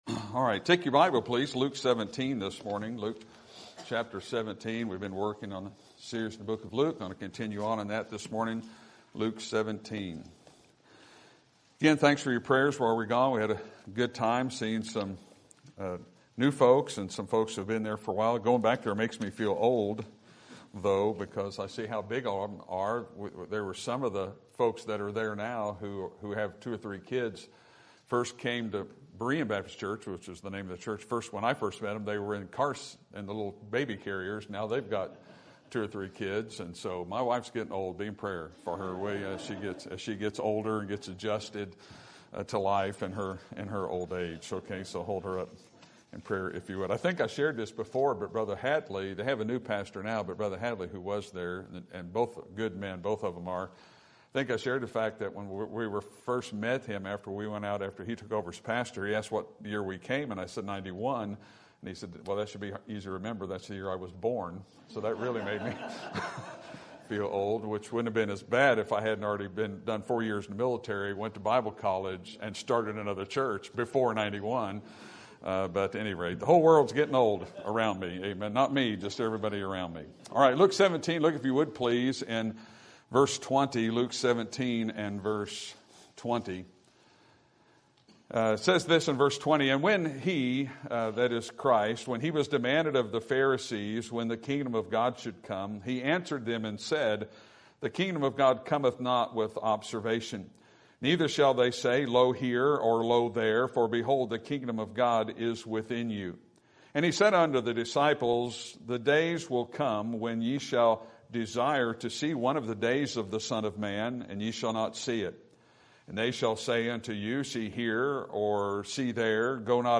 Sermon Topic: Book of Luke Sermon Type: Series Sermon Audio: Sermon download: Download (18.82 MB) Sermon Tags: Luke Jesus Kingdom Return